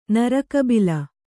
♪ naraka bila